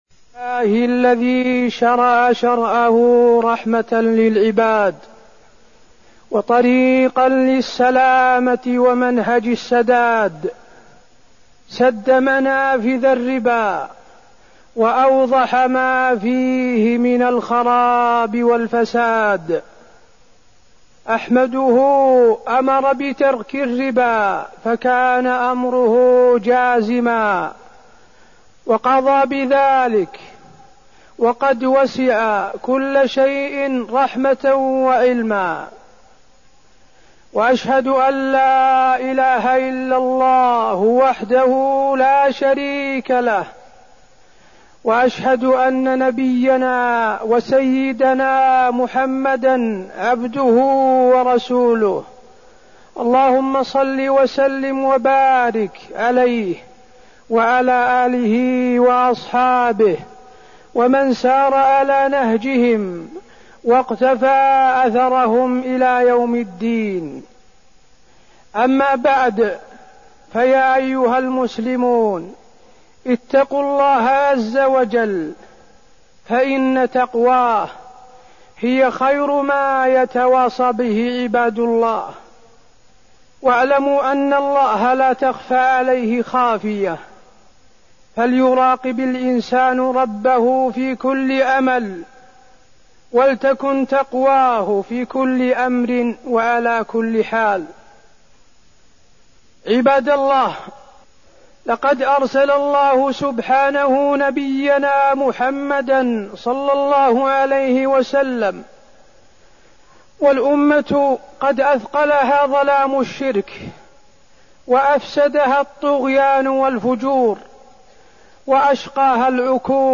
تاريخ النشر ٢٧ جمادى الأولى ١٤١٩ هـ المكان: المسجد النبوي الشيخ: فضيلة الشيخ د. حسين بن عبدالعزيز آل الشيخ فضيلة الشيخ د. حسين بن عبدالعزيز آل الشيخ الربا